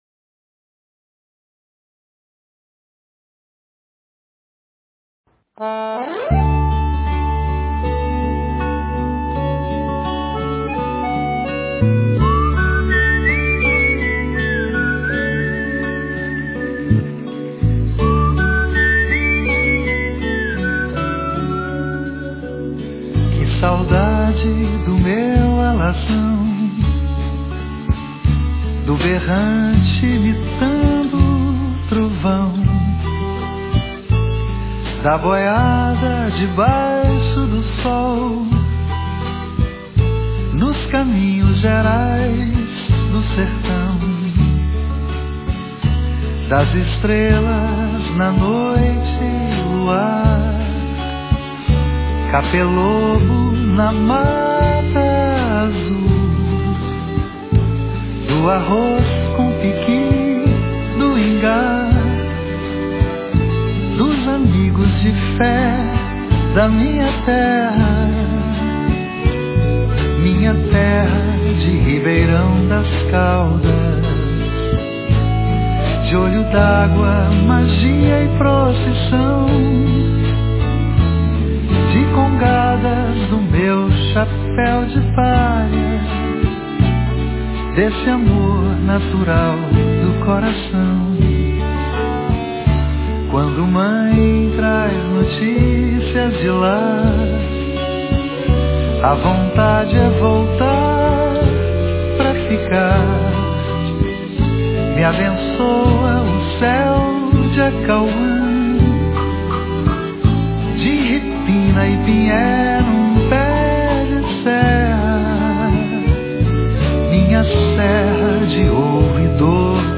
no final tem um Link para Abrir a Música que é Cantada.
essa modinha de viola me deu uma tremenda saudade...